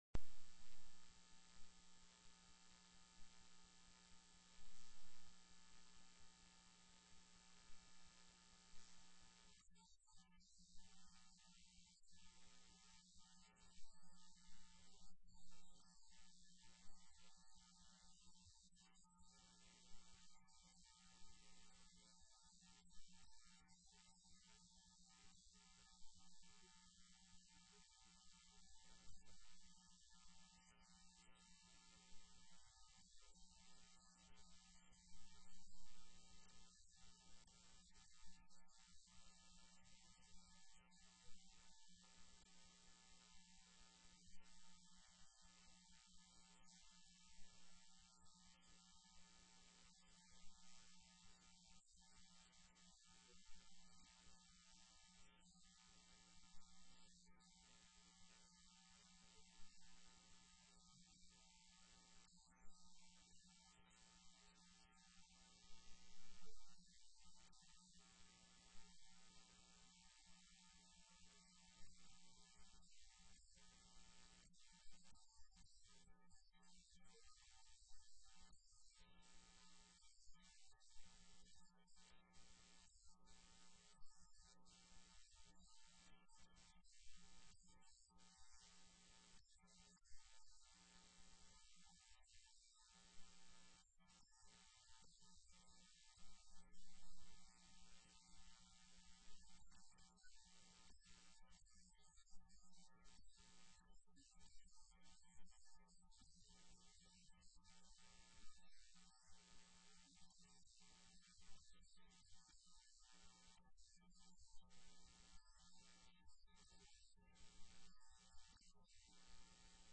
3:14:22 PM Representative Foster spoke in support of the bill. REPRESENTATIVE CATHY MUNOZ, SPONSOR, addressed specific questions that had been asked previously by committee members. She referred to additional materials covering cost comparison data, due diligence information, and a construction analysis.